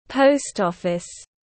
Post office /ˈpəʊst ˌɒf.ɪs/
Post-office.mp3